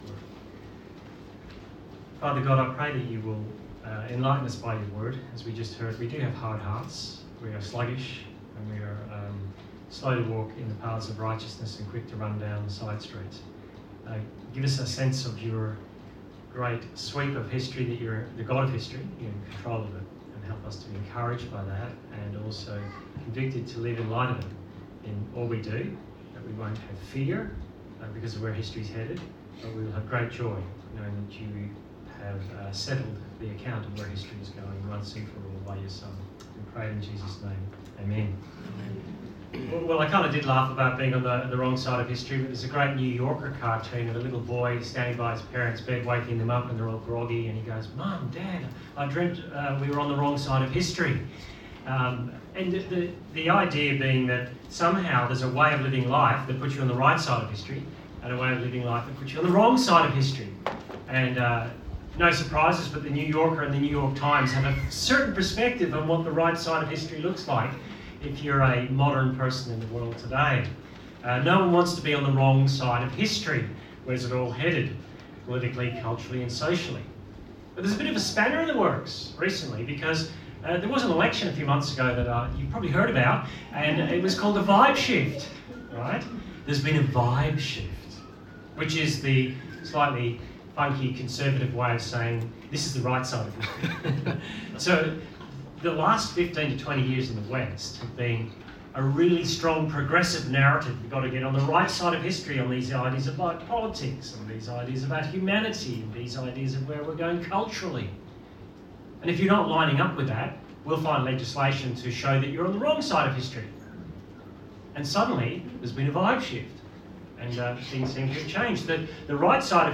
Service Type: Church Camp Talk